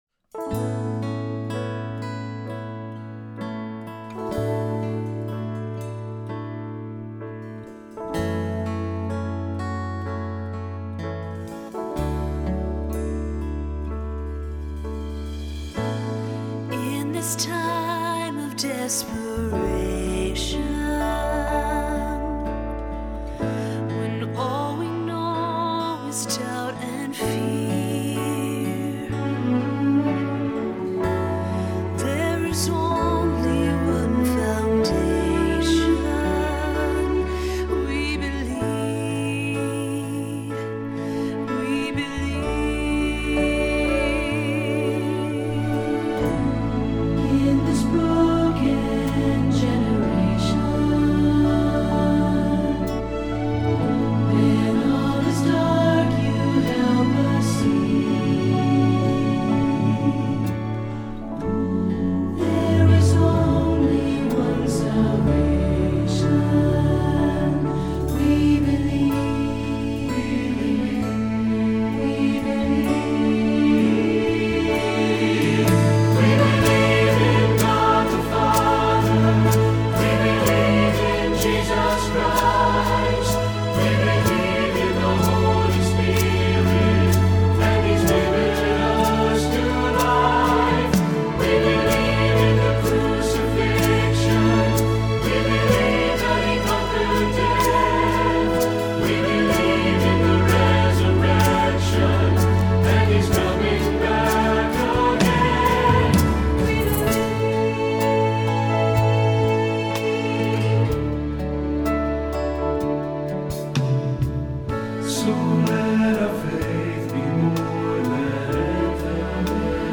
Choral Church